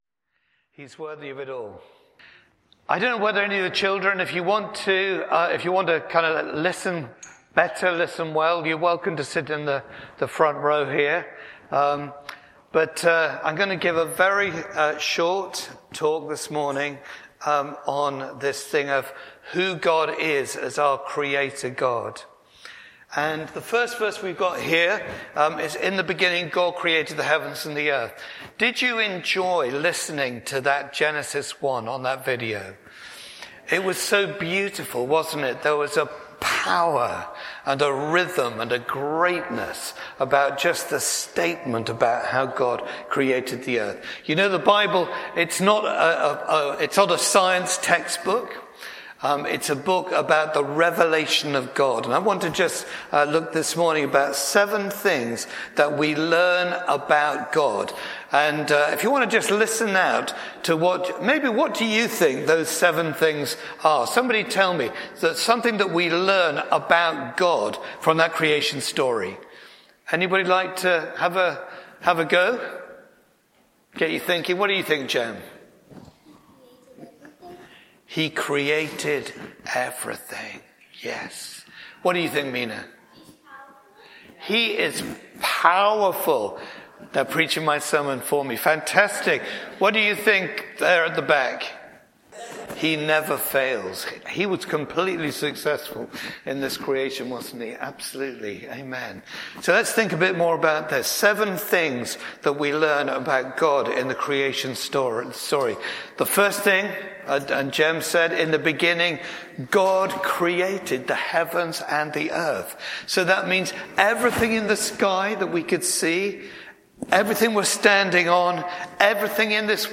The Creator God, Bassett Street Sermons